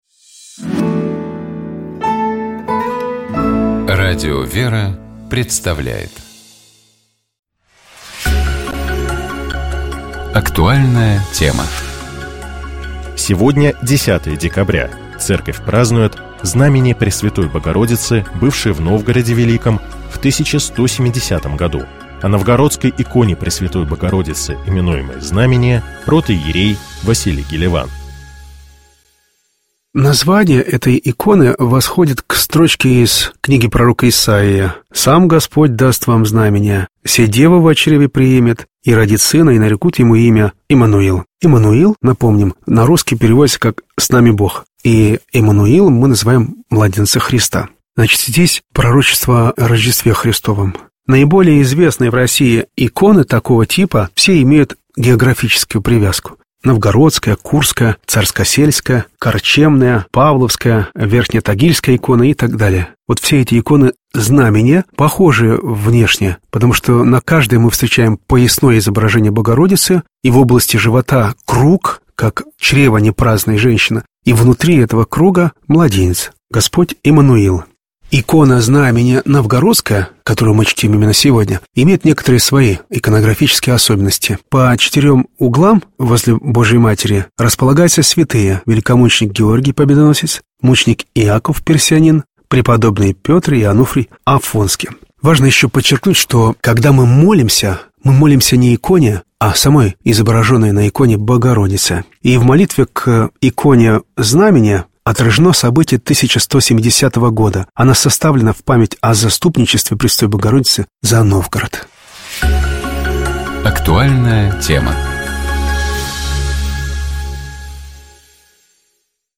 Этой беседой мы продолжаем цикл из пяти бесед о значении и истории появления семи таинств Церкви.